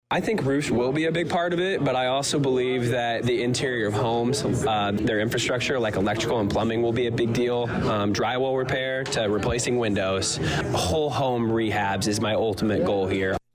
evening’s Public Works Committee meeting.